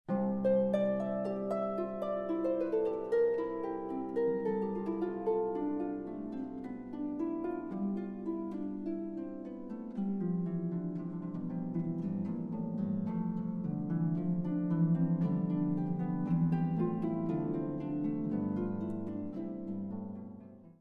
for 2 pedal harps